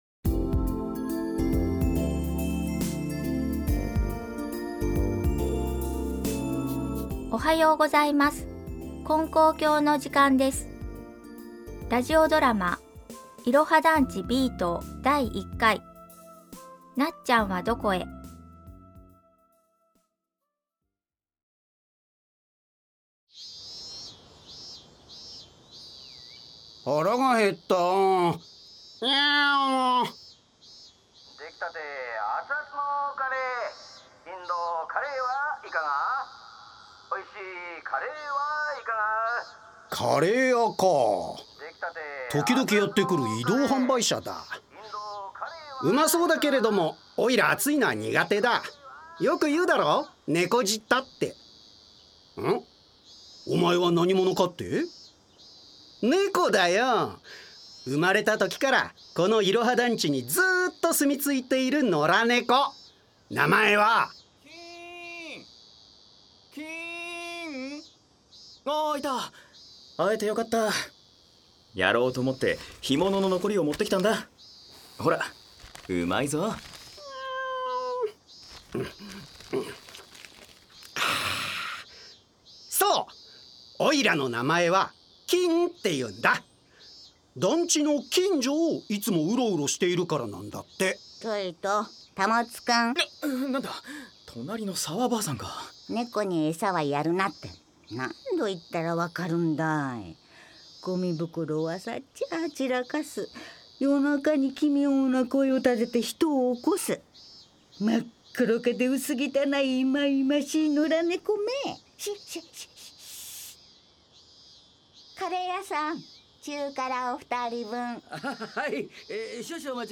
●ラジオドラマ「いろは団地Ｂ棟」